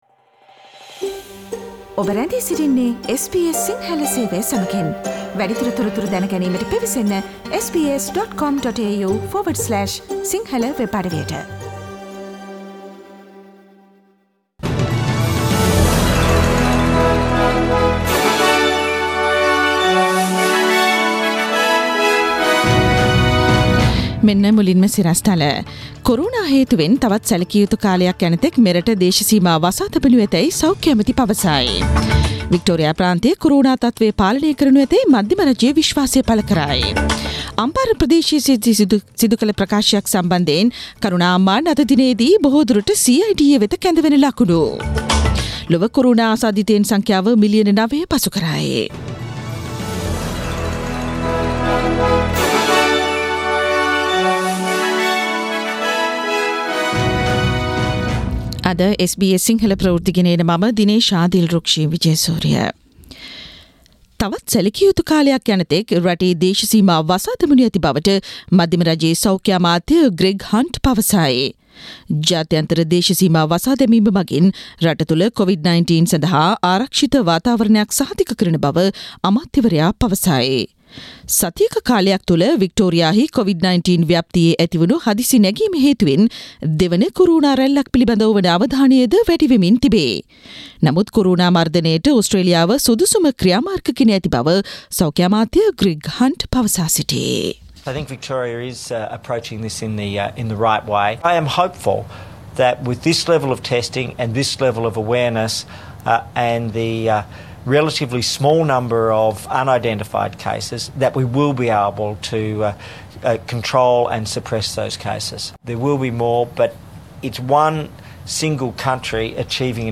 Daily News bulletin of SBS Sinhala Service: Tuesday 23 June 2020